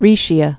(rēshē-ə, -shə)